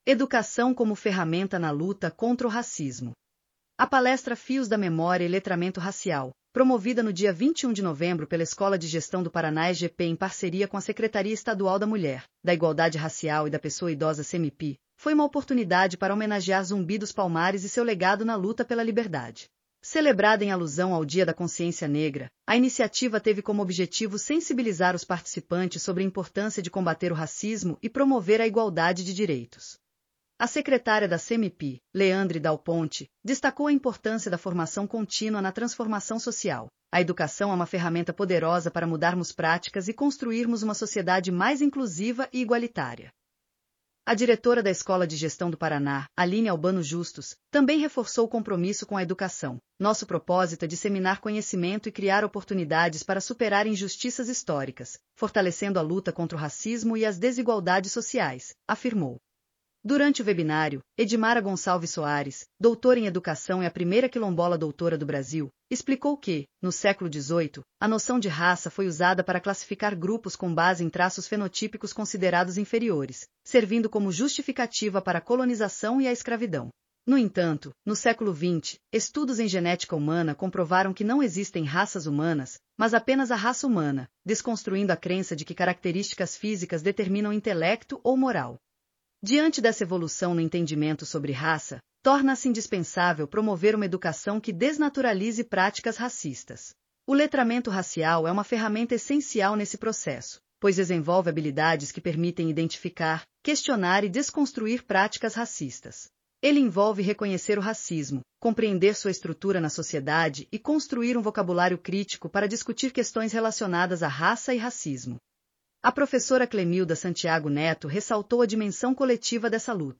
audionoticia_educacao_como_ferramenta_na_luta.mp3